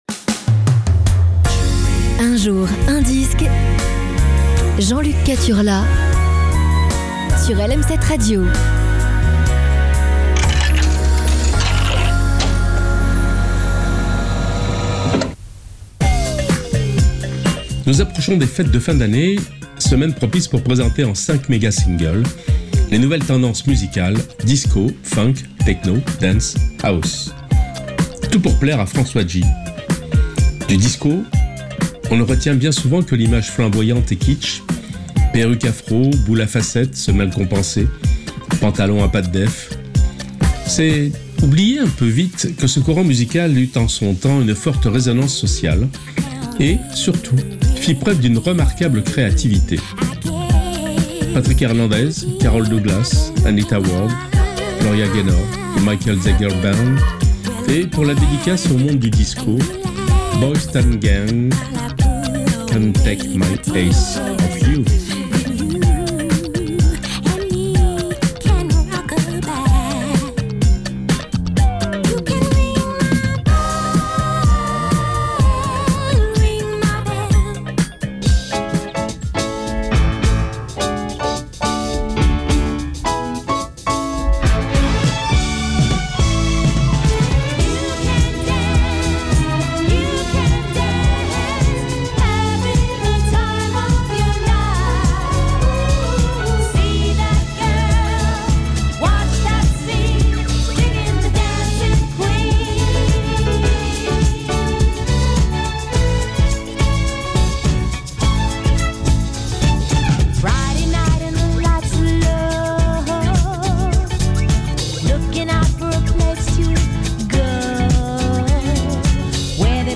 aujourd'hui c'est DISCO